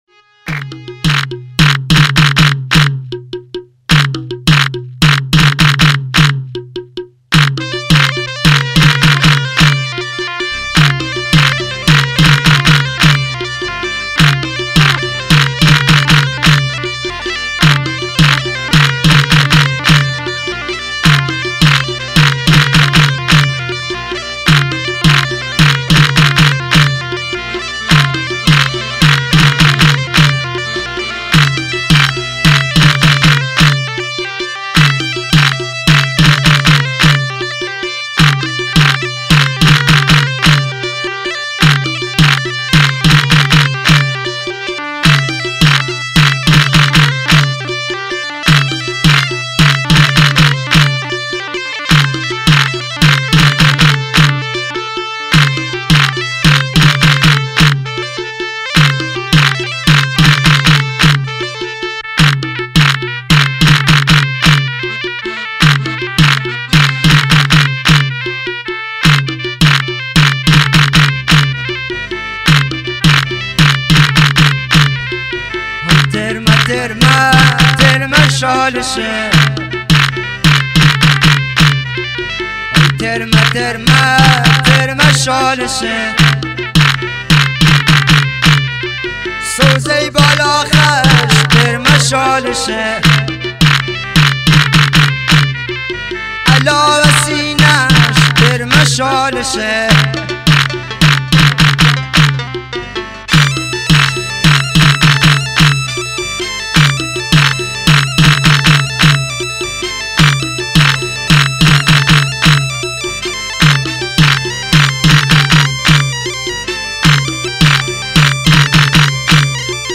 (شاد عروسی)